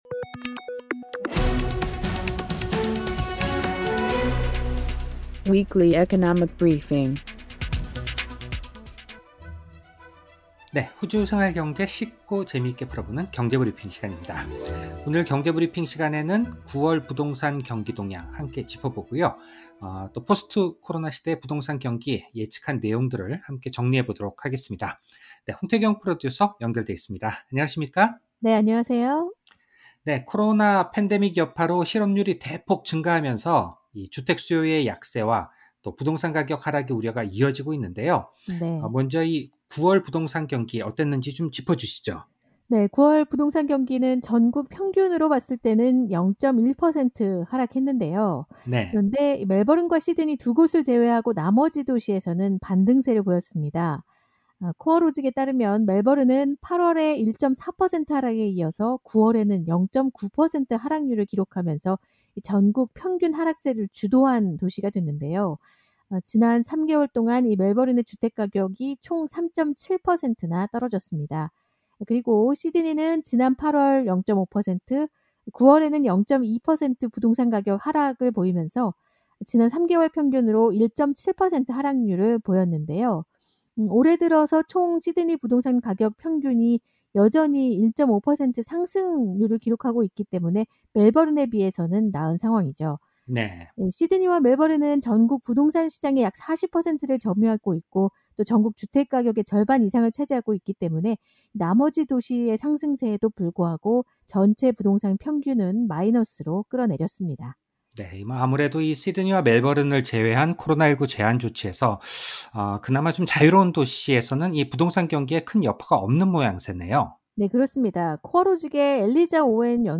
korean_1510_economy_briefing.mp3